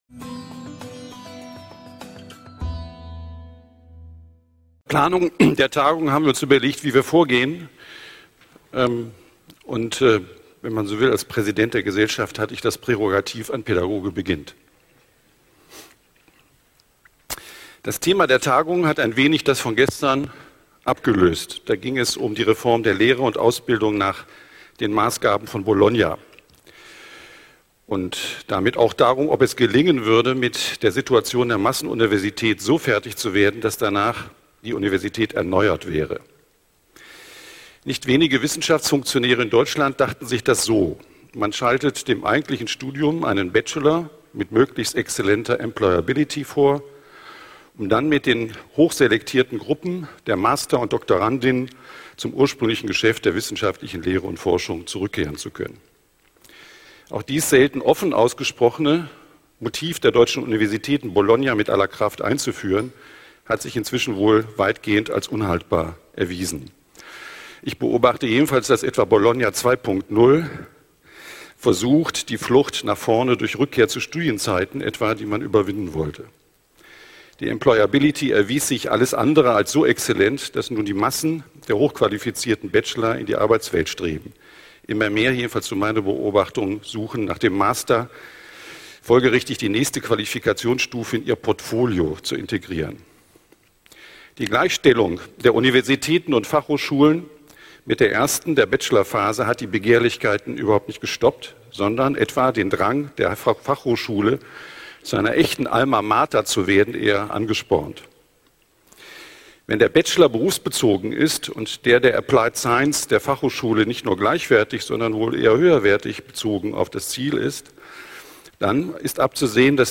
Location: Campus Westend Casino Festsaal